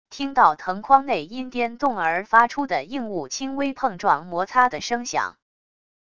听到藤筐内因颠动而发出的硬物轻微碰撞摩擦的声响wav音频